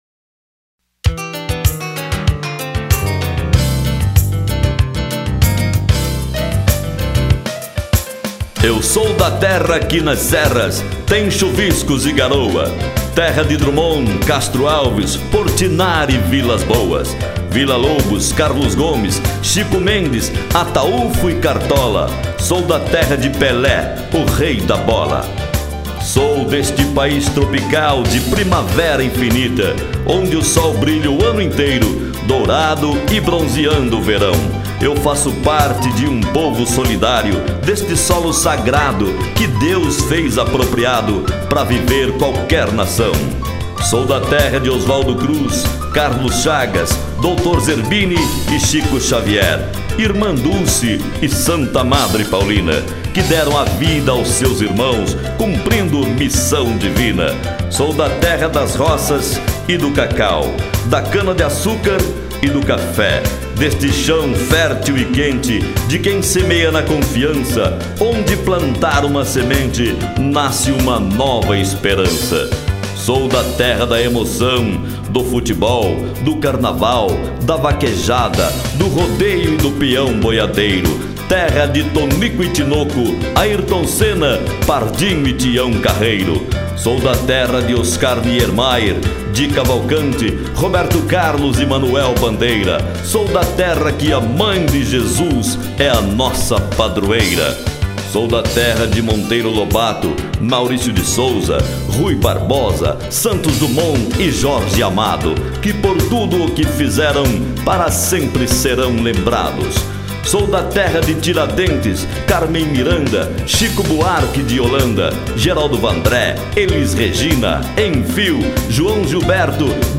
EstiloLocutores